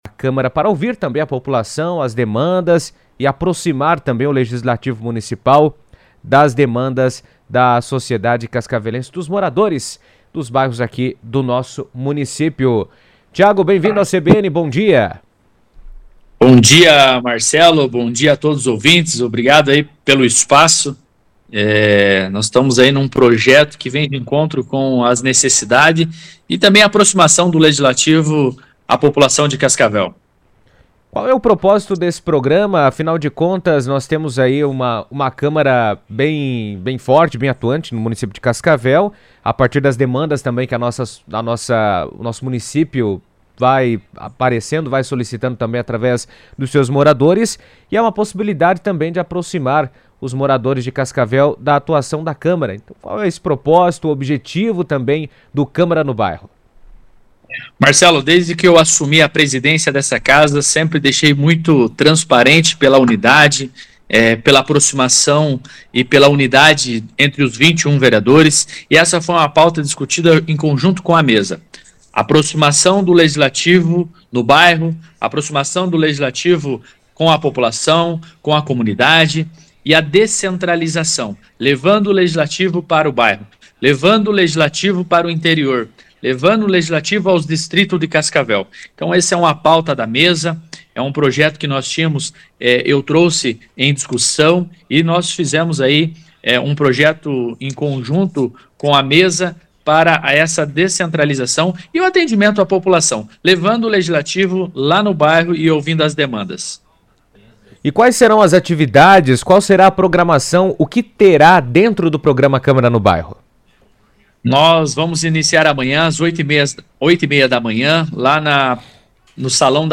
Em entrevista à rádio CBN, o presidente da Câmara de Vereadores, Tiago Almeida, ressaltou a importância de ouvir as demandas dos moradores e fortalecer a participação popular nas decisões da cidade.